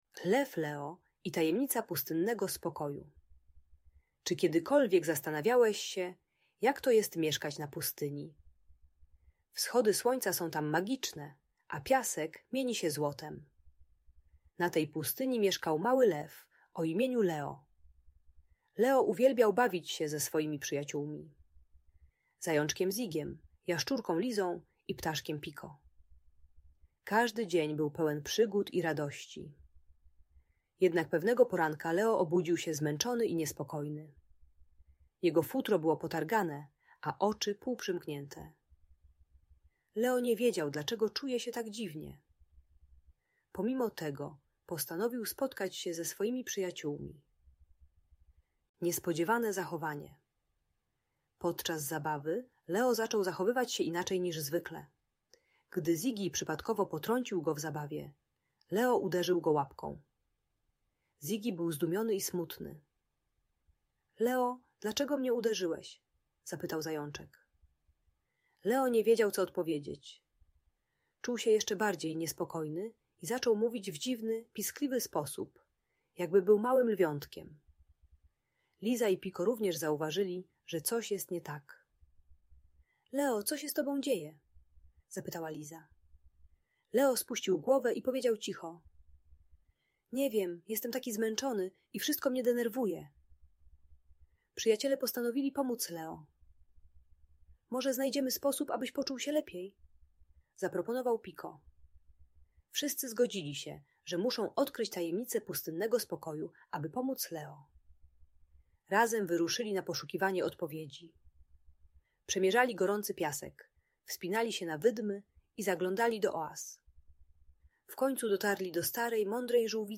Historia o Lwie Leo i Tajemnicy Pustynnego Spokoju - Rodzeństwo | Audiobajka